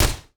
etfx_explosion_bullet.wav